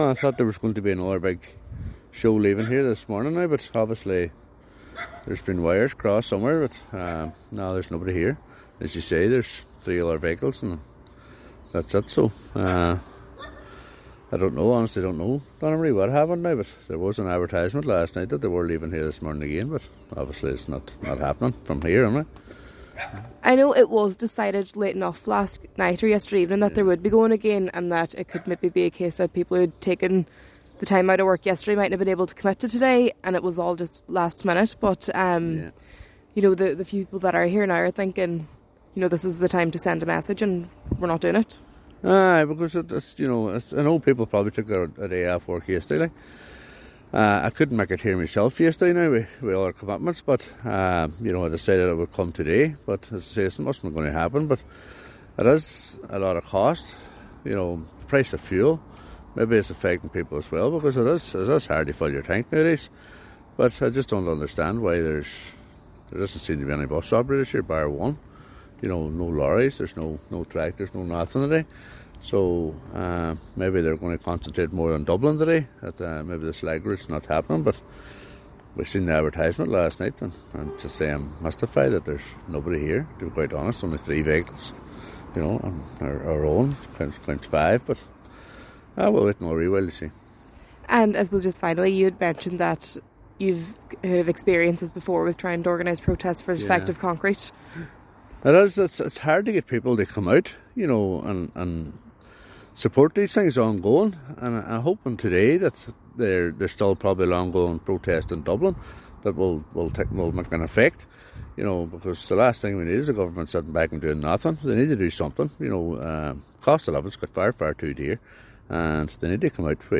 100% Redress Councillor Tomas Sean Devine says he thought there would have been a larger turnout……………